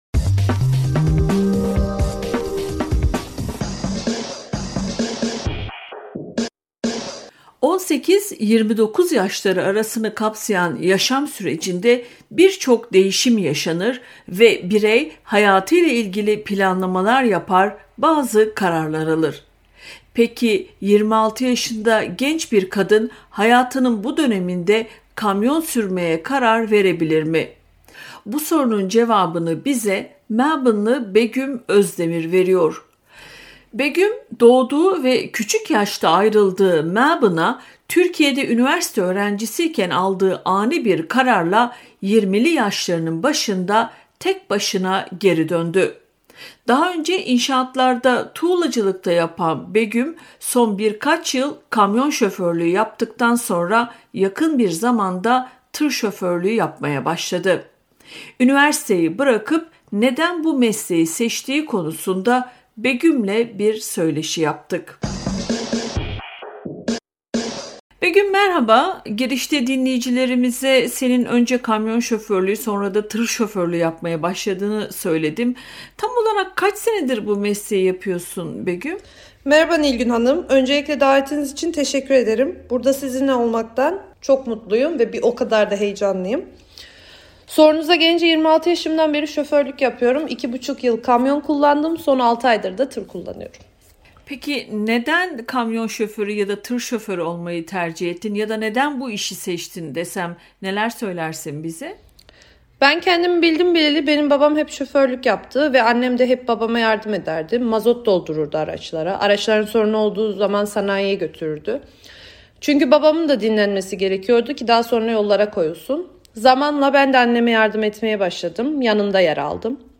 bir söyleşi yaptık.